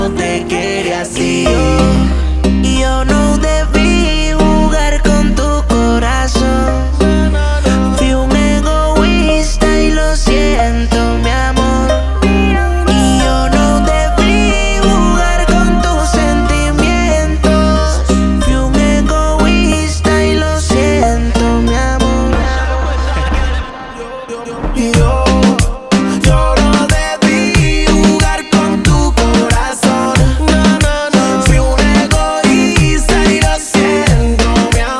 # Latin Urban